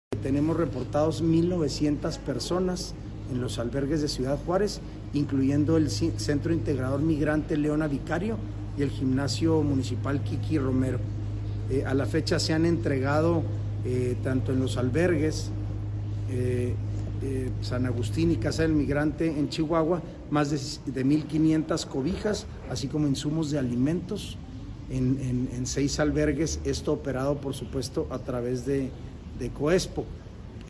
AUDIO: SANTIAGO DE LA PEÑA GEAJEDA, TITULAR DE LA SECRETARÍA GENERAL DE GOBIERNO (SGG)